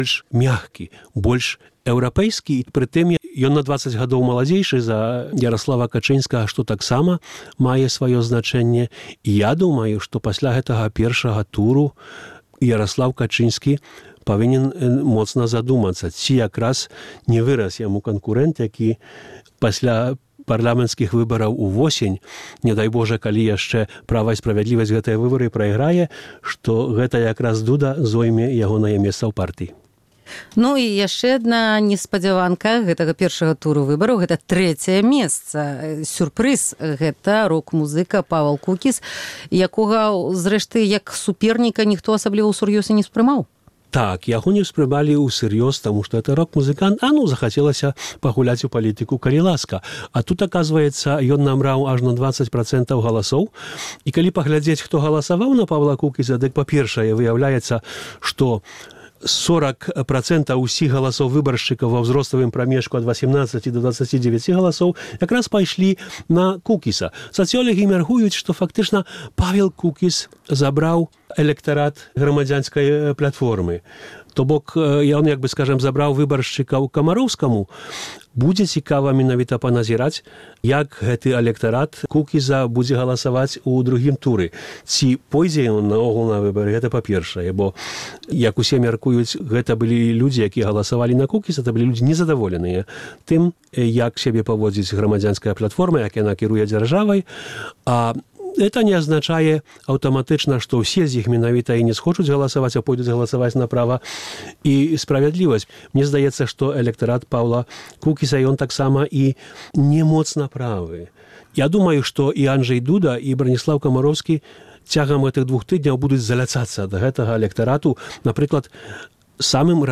Роля перамогі ў Другой сусьветнай — вынік масіраванай прапаганды альбо народнай памяці? Ці варта Беларусі, як Украіне, прыняць пакет законаў супраць савецкай спадчыны? Па гэтых пытаньнях ў Праскім акцэнце вядуць спрэчку